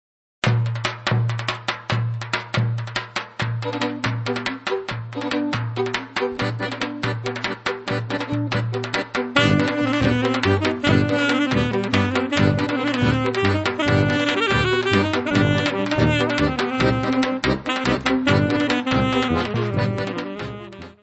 traditional Yiddish music
violino
clarinete
saxofone
acordeão
tuba
tapan, darabukka.
Music Category/Genre:  World and Traditional Music